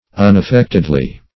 [1913 Webster] -- Un`af*fect"ed*ly, adv.